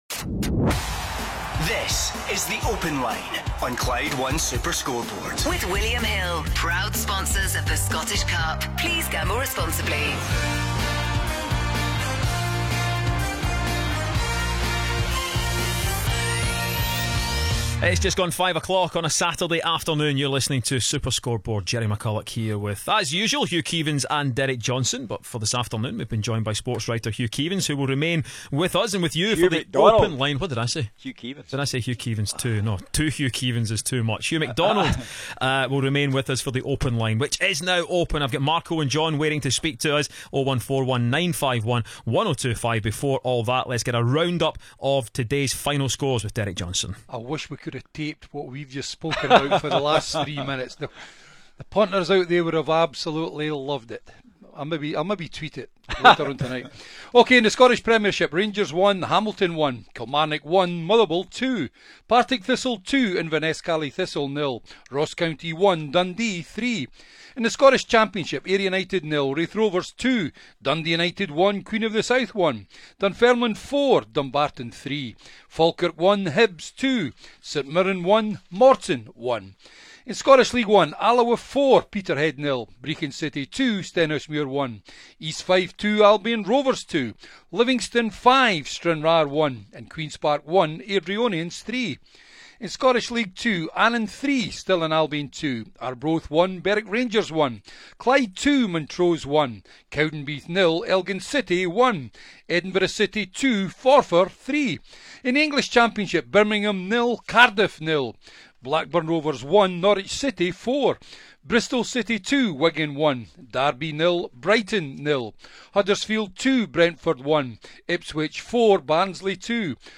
Clyde phone in yesterday was hilarious ...